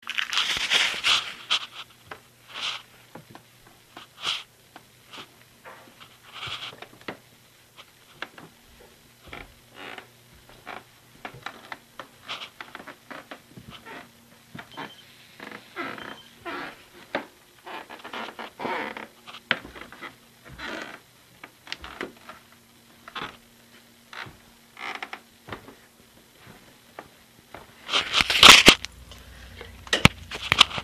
creaking – Hofstra Drama 20 – Sound for the Theatre
Location: Outside of Vander Poel hall into the lobby, 2/21/18 around 11:20am
Sounds heard: My sneakers on the damp concrete (my favorite sound here, it was interesting), wind, the card-swipe machine beeping, the front door opening, the turnstile creaking